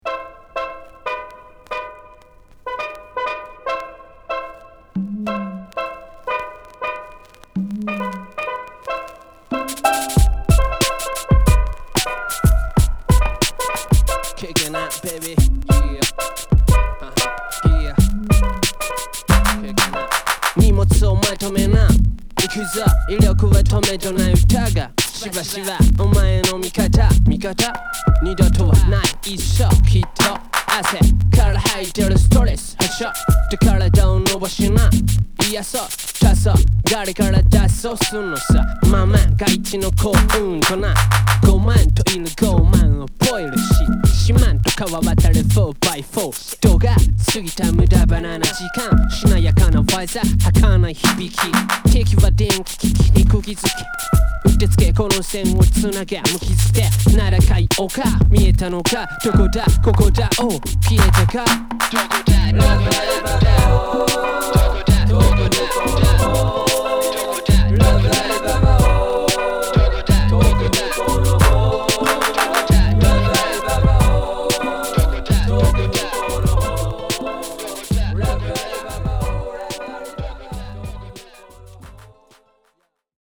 ホーム HIP HOP JAPANESE 12' & LP T